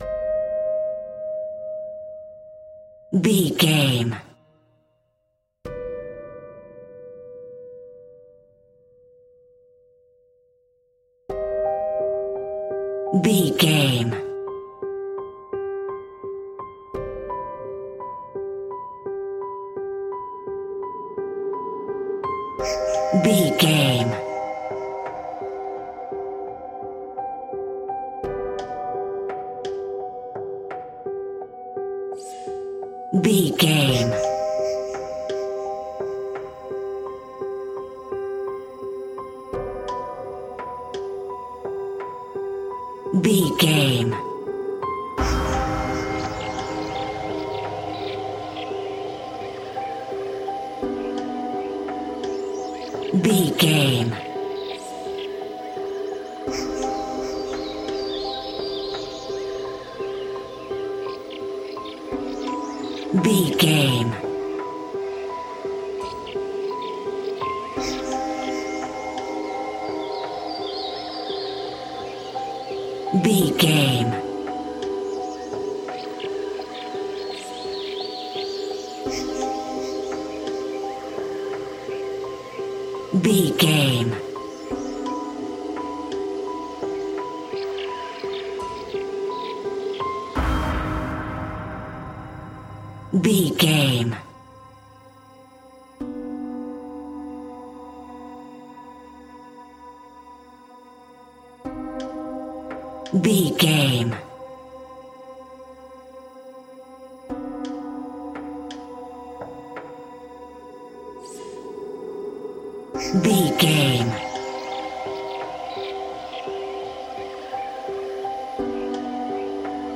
Aeolian/Minor
Slow
ominous
dark
haunting
eerie
piano
synthesiser
horror music
Horror Pads